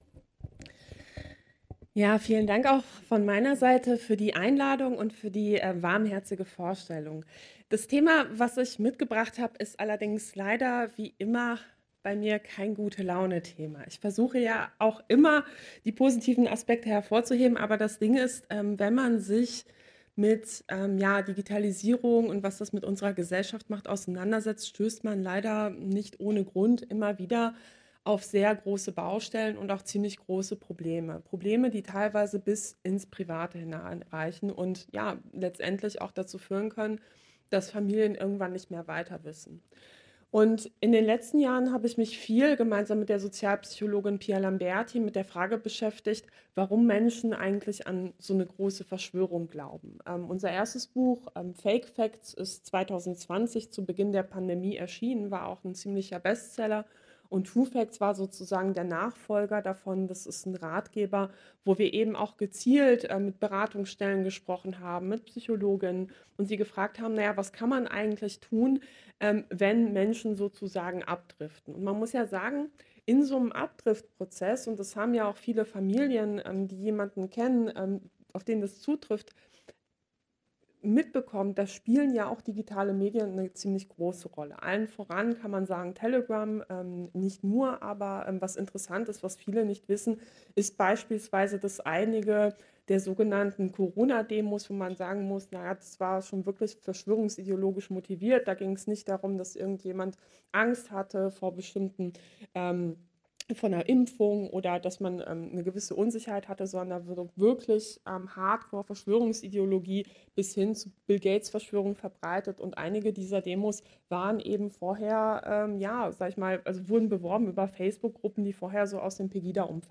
Gespräch
Audio-Mitschnitt der Veranstaltung (Dauer: 1:33 h): MP3 in neuem Fenster öffnen | Download MP3 (99 MB)